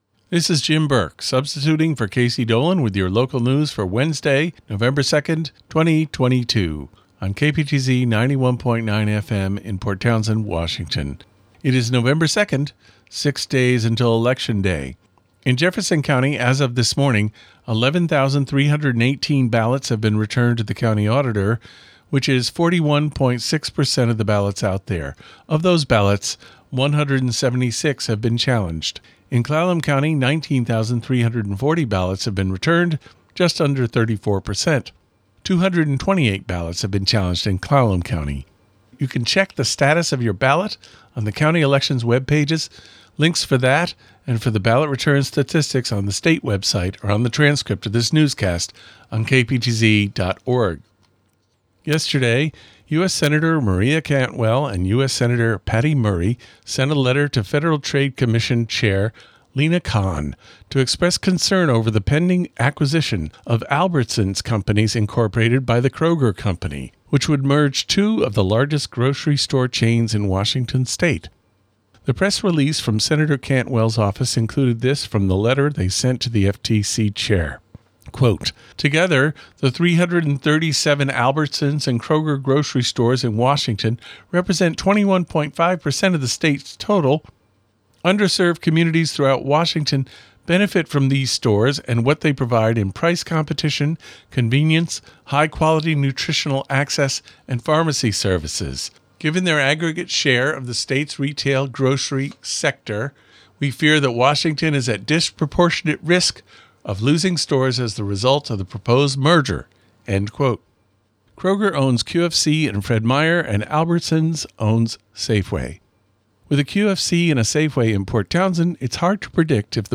221102 Local News Wednesday